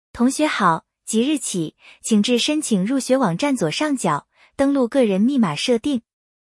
文字轉語音